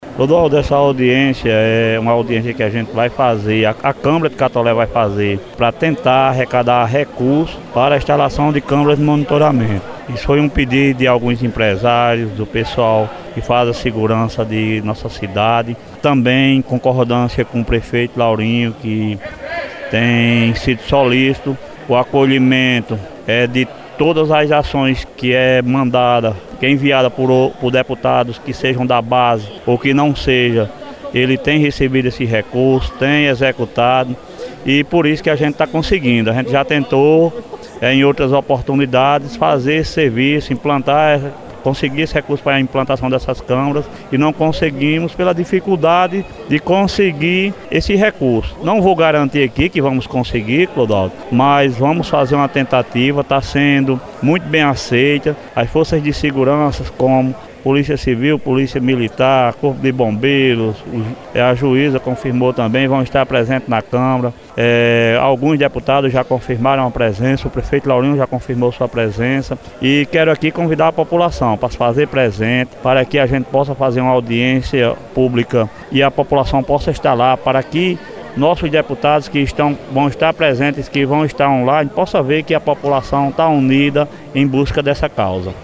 🎙 Ouça o vereador Cleverlando Barreto falando sobre a audiência pública:
vereador-Cleverlando-Barreto.mp3